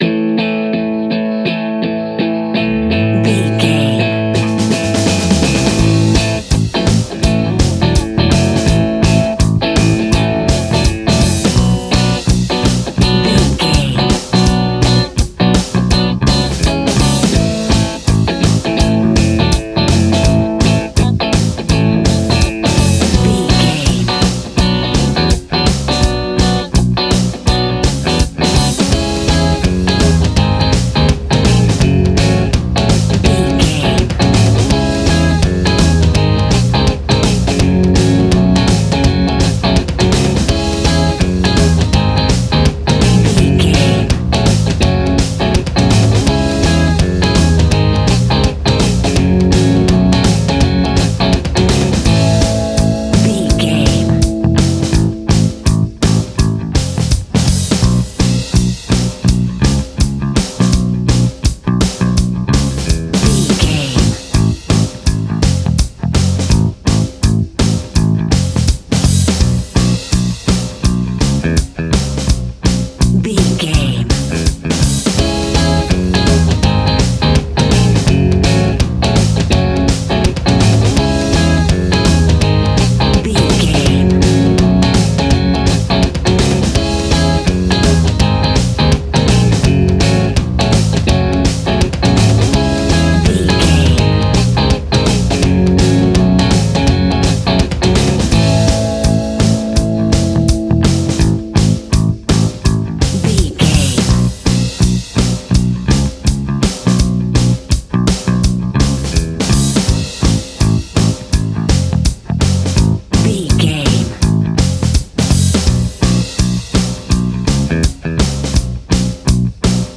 Aeolian/Minor
D♭
groovy
happy
electric guitar
bass guitar
drums
piano
organ